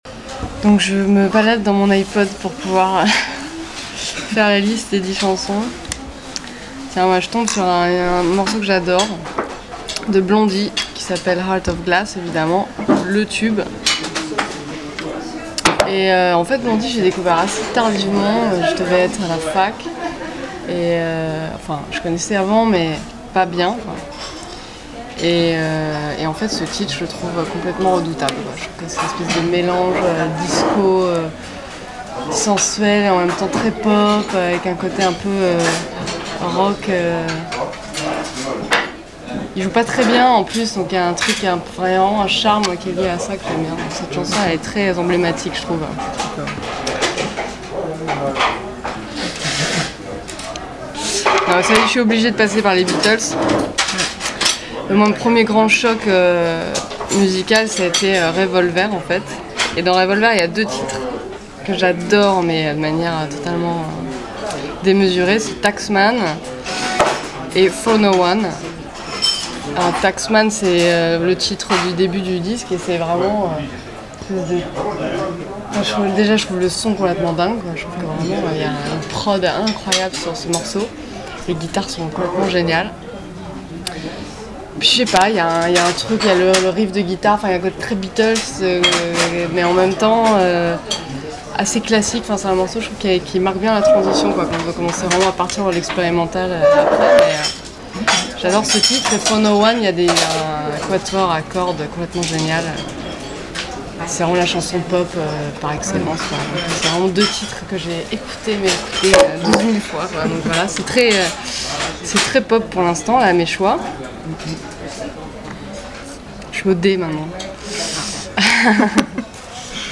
groupe rock
influences très britpop